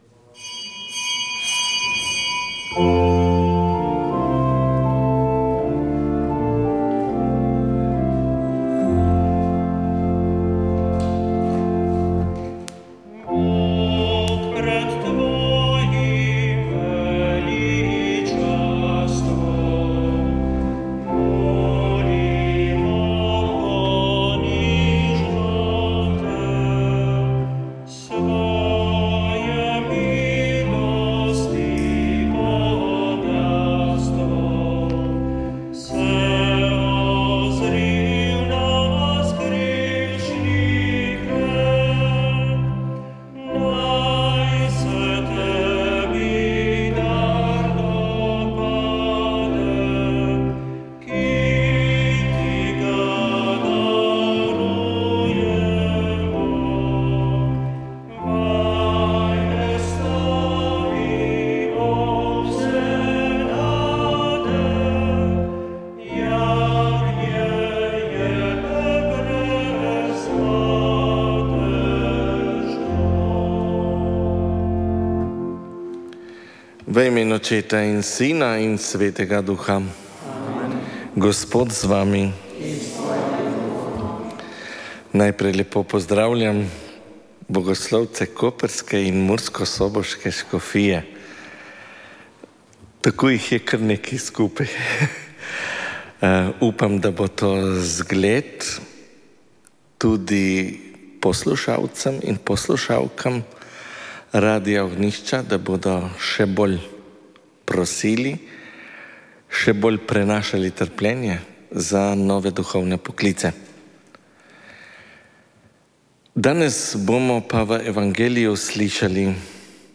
Sv. maša iz cerkve svetega Mihaela Grosuplje 24. 8.
Pri maši je sodeloval otroški pevski zbor